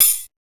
FX140CYMB2-L.wav